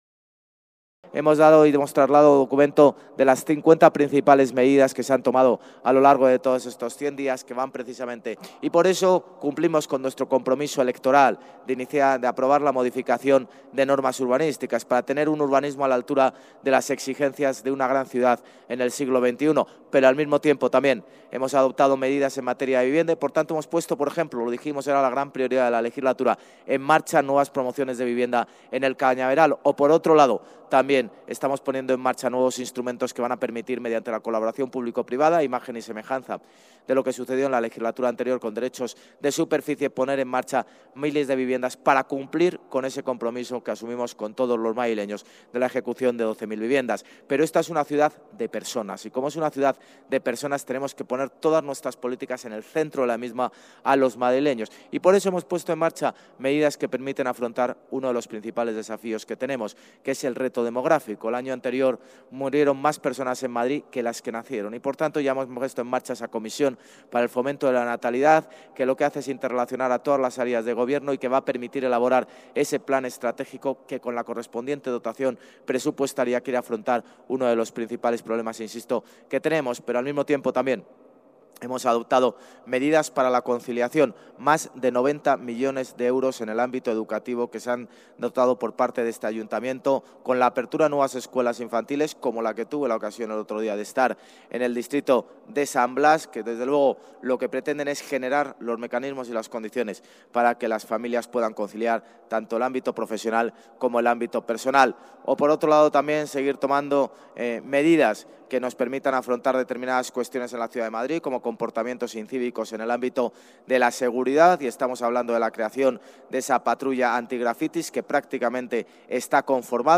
Nueva ventana:Almeida durante su intervención en este acto